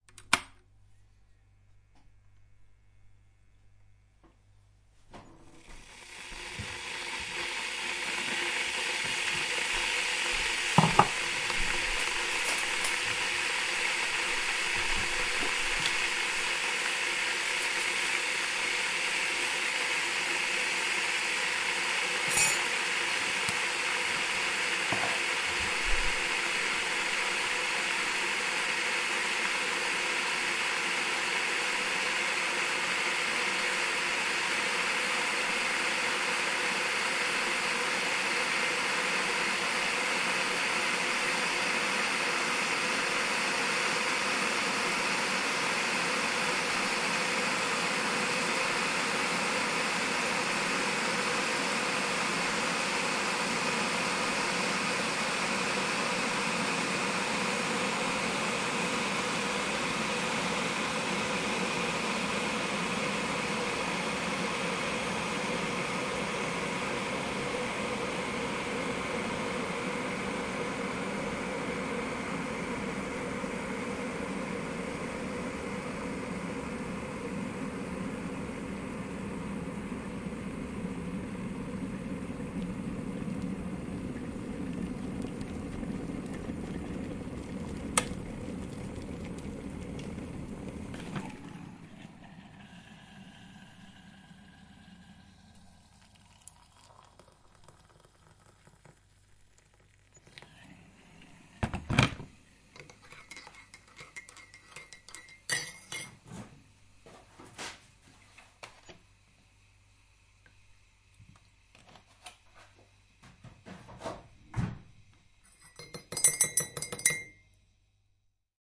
制作咖啡
描述：打开水壶，煮沸时将一个杯子放在旁边，然后放入一勺速溶咖啡。当水壶沸腾时，将水倒入杯中并搅拌。然后加入牛奶并再次搅拌。
Tag: 速溶咖啡 煮 - 水 搅拌均匀 水壶